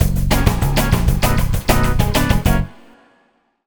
Swinging 60s 5 Full-F#.wav